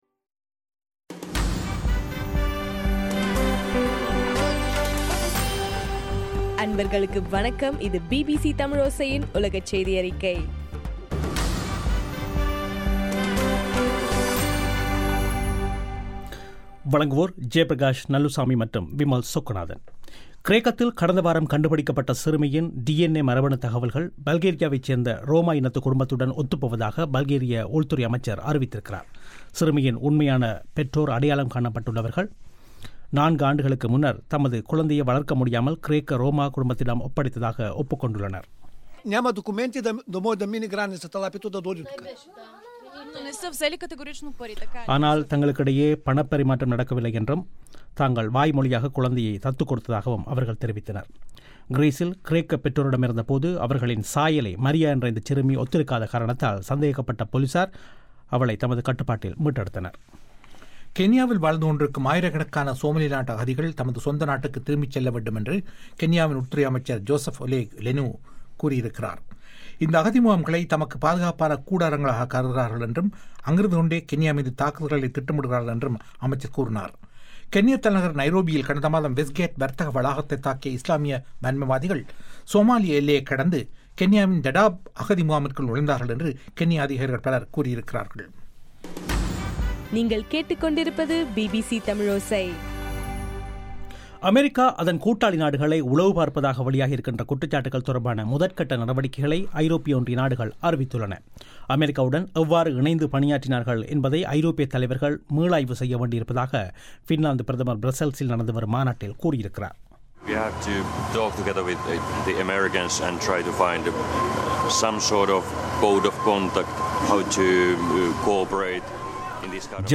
அக்டோபர் 25 2013 பிபிசி தமிழோசை உலகச் செய்திகள்